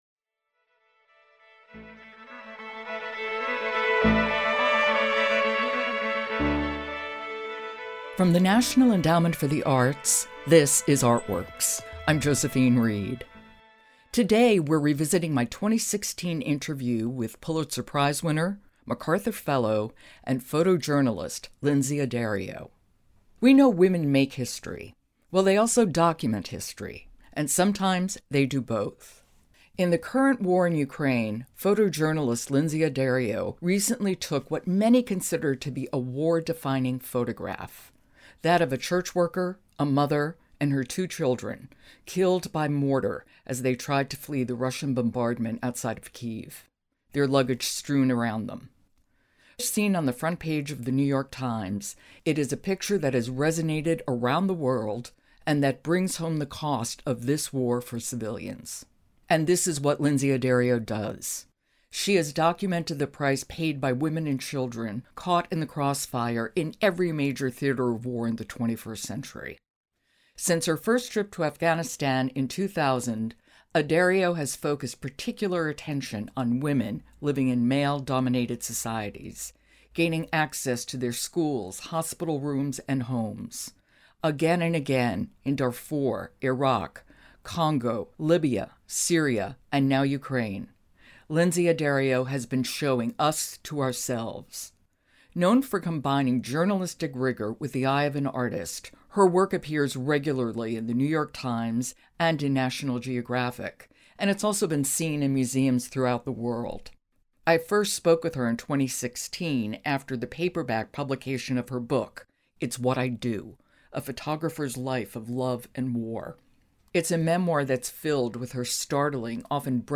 Today—we’re revisiting my 2016 interview with Pulitzer-Prize winner, MacArthur Fellow, and photojournalist Lynsey Addario.